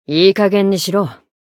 灵魂潮汐-迦瓦娜-互动-厌恶的反馈.ogg